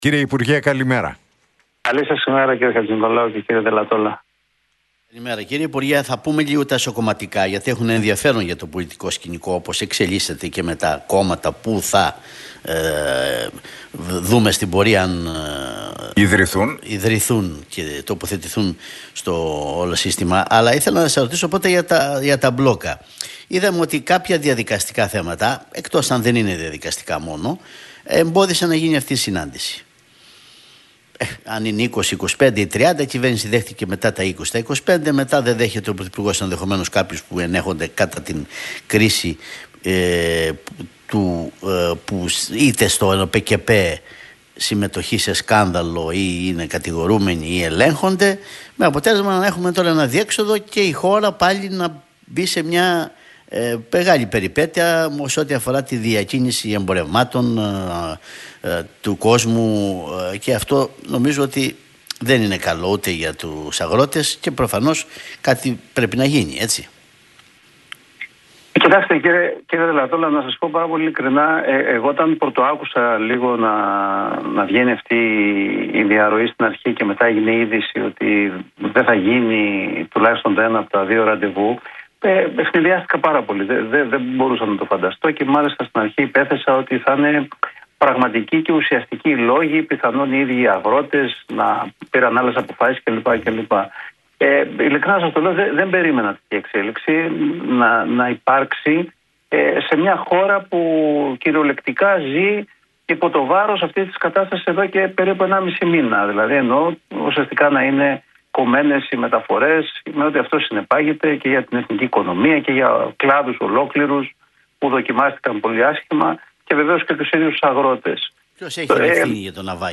είπε ο Γιάννης Ραγκούσης, μέλος της Πολιτικής Γραμματείας του ΣΥΡΙΖΑ, μιλώντας στην εκπομπή του Νίκου Χατζηνικολάου